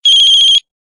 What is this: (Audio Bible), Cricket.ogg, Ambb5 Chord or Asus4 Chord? Cricket.ogg